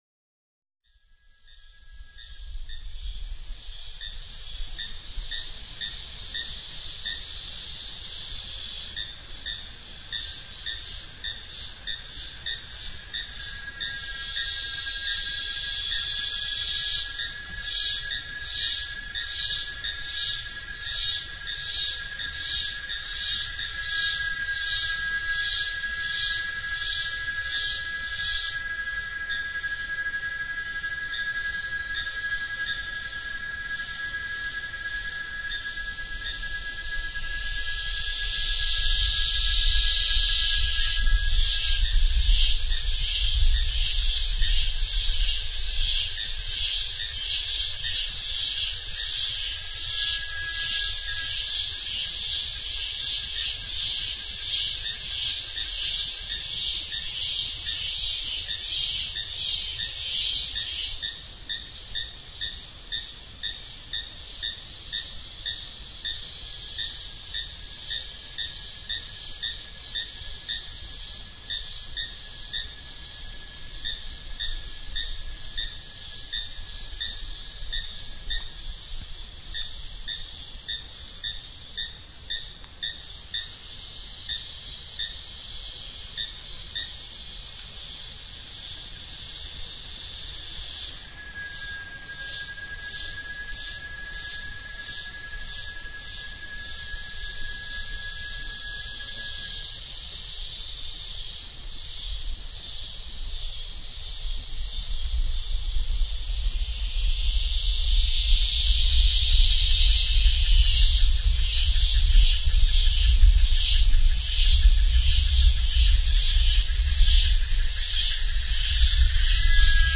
Birds, frogs and many hidden insects are the musicians.
In the Imataca jungle, State of Bolívar